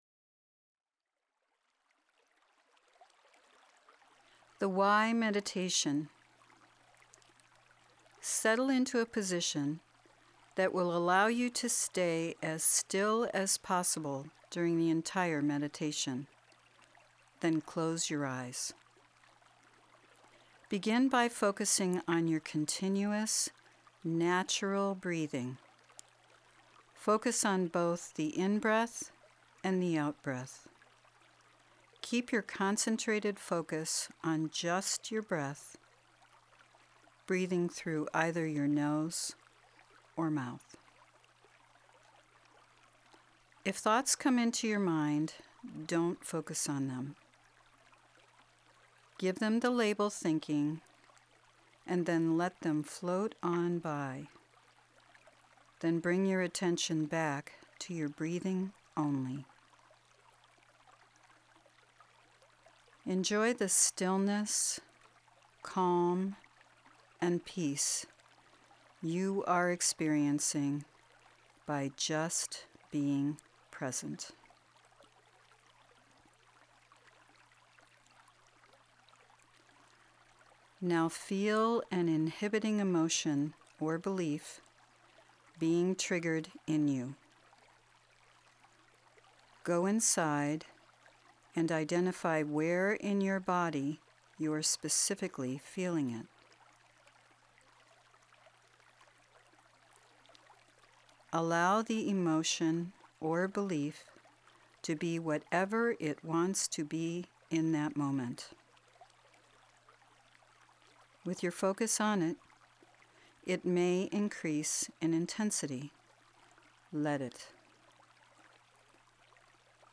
Download Meditation MP3